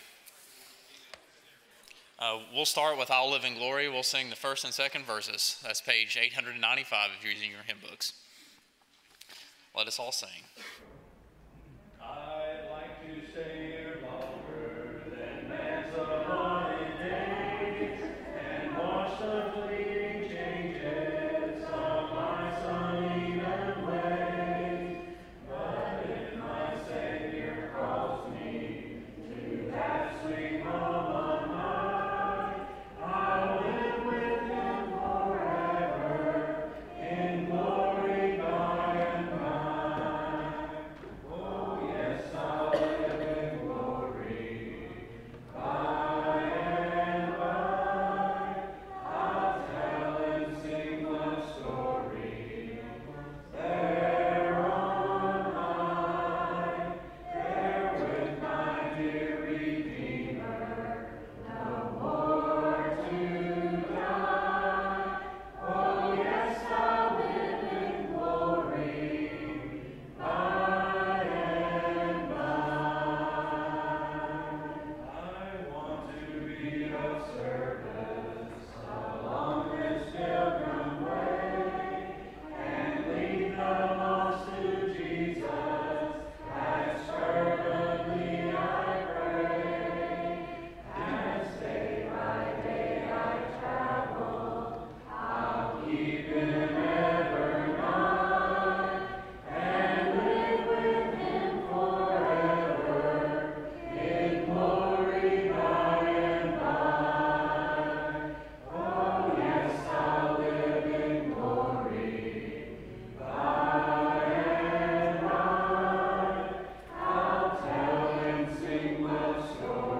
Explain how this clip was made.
Job 1:8, English Standard Version Series: Sunday PM Service